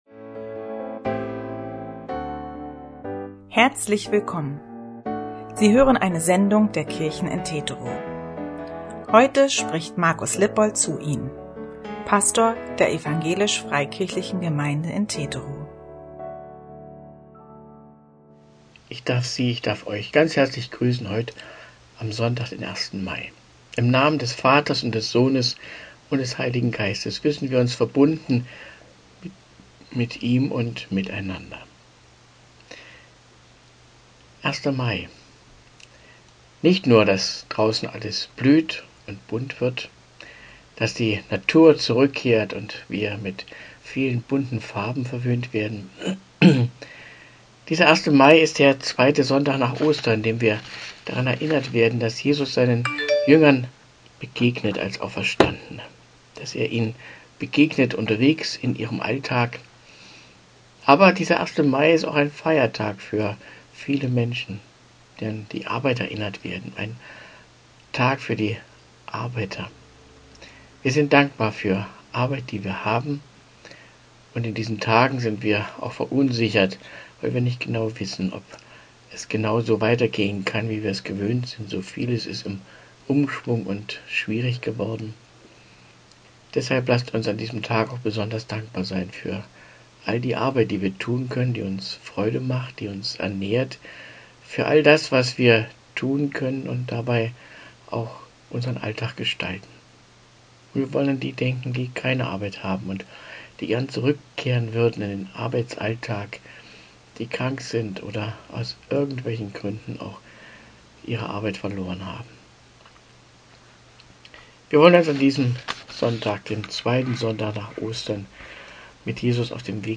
Audio-Andacht vom 01.05.2022